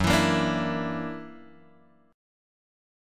F#+7 chord {2 1 0 3 3 0} chord